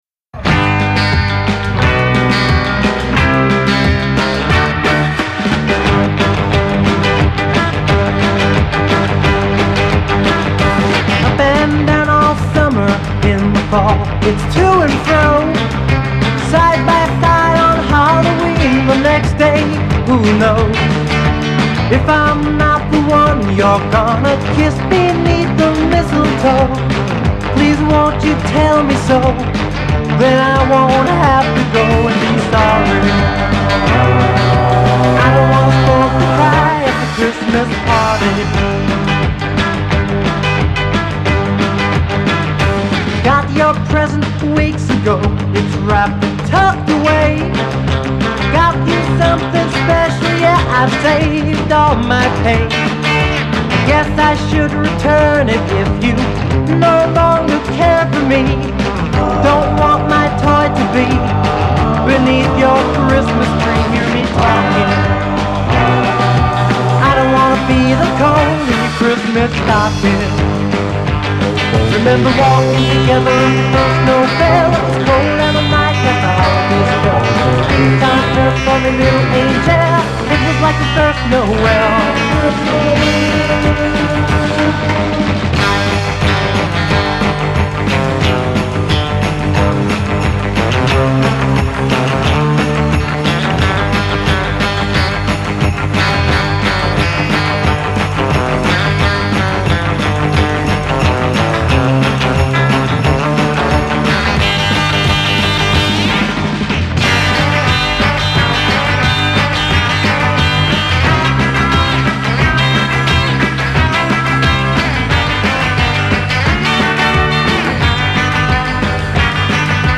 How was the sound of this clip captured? studio recordings